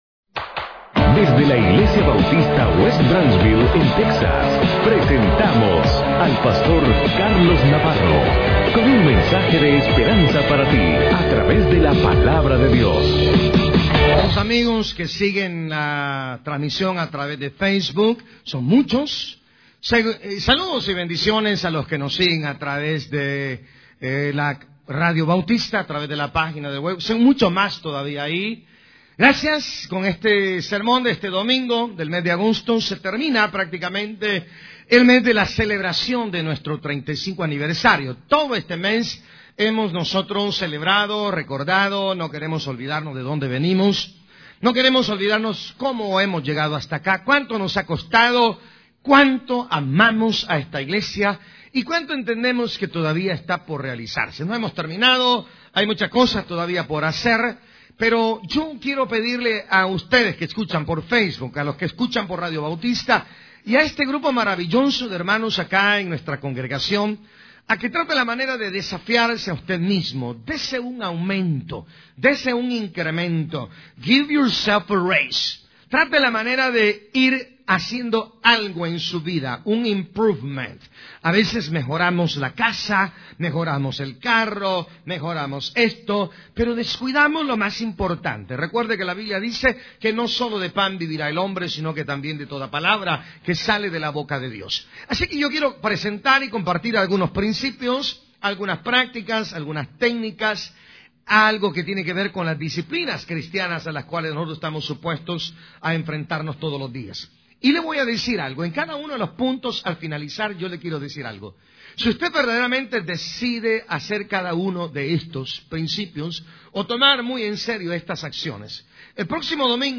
Sermones en audio y vídeo, fotografías, eventos y mucho más ¡Queremos servirle!